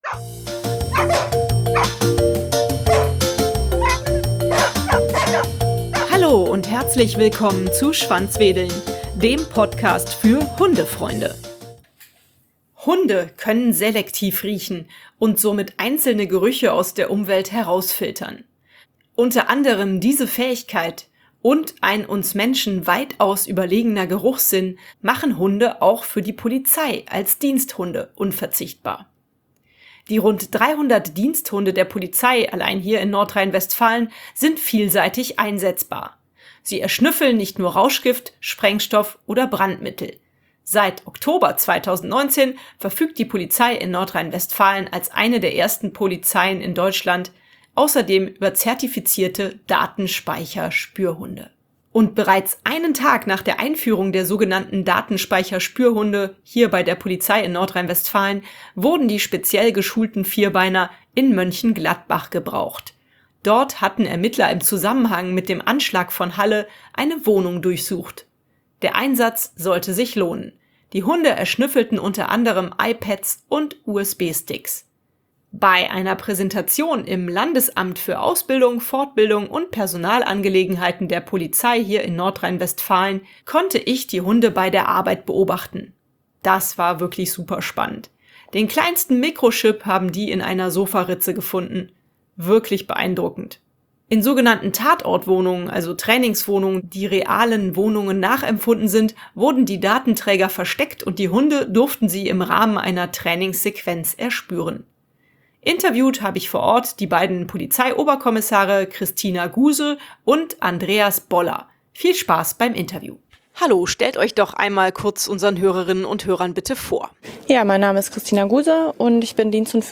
Bei einer Präsentation im Bildungszentrum in Neuss hier in Nordrhein-Westfalen konnte ich die Hunde bei der Arbeit beobachten.